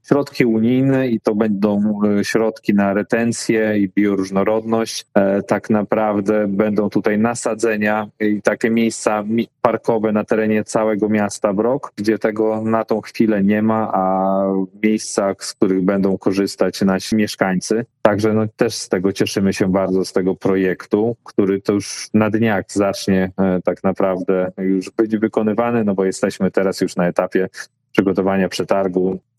Jak mówi burmistrz Sebastian Oczkowski, dofinansowanie przyznano na realizację projektu „Poprawa retencji i przeciwdziałanie suszy na terenie Gminy Brok”.